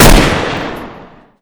Index of /server/sound/weapons/dod_m1928
mac10-1.wav